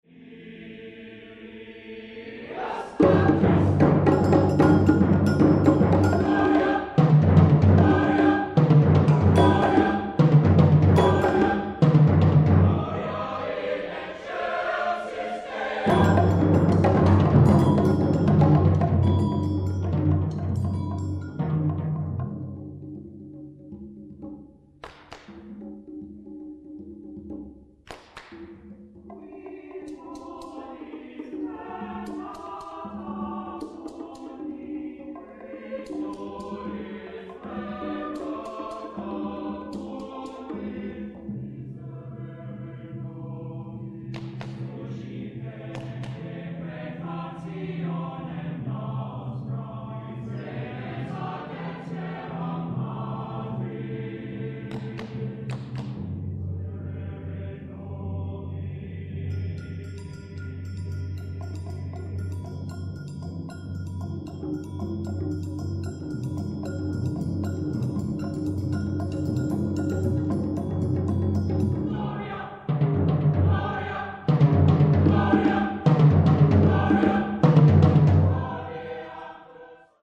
A missa brevis for SATB choir and percussion quartet.